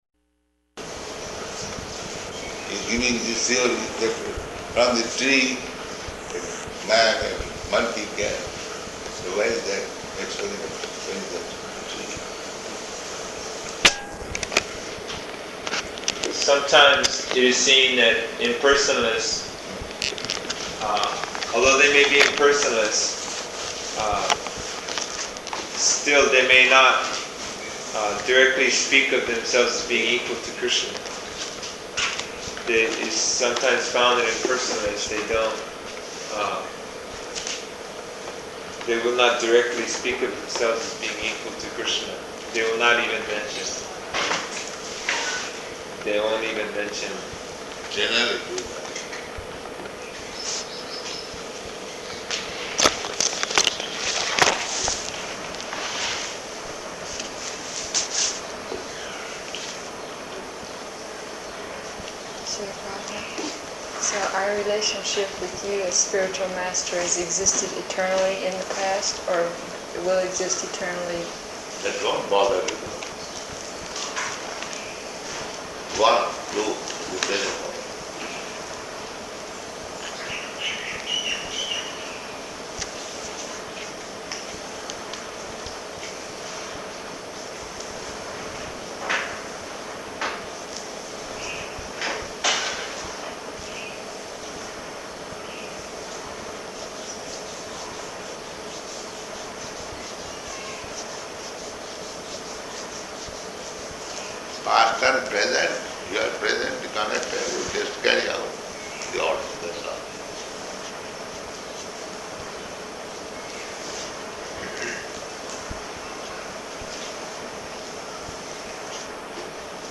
Room Conversation
Room Conversation --:-- --:-- Type: Conversation Dated: June 12th 1975 Location: Honolulu Audio file: 750612R1.HON.mp3 Prabhupāda: You giving this theory that from the tree a man and a monkey came.